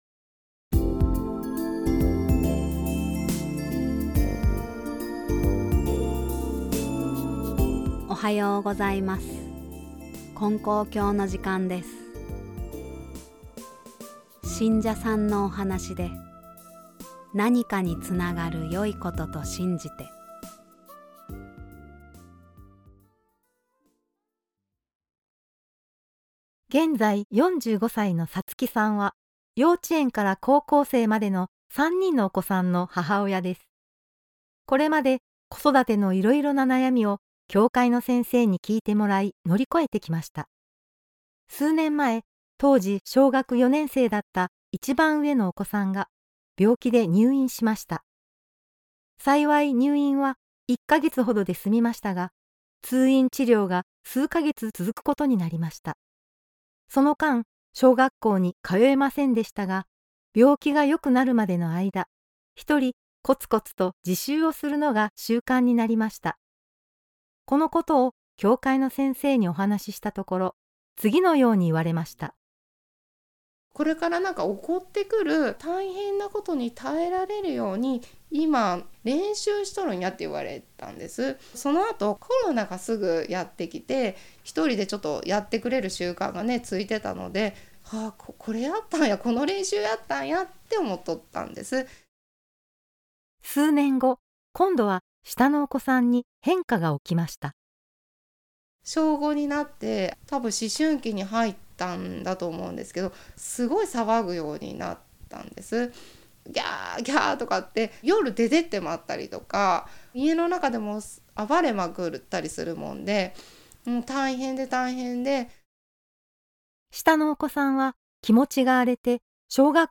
●信者さんのおはなし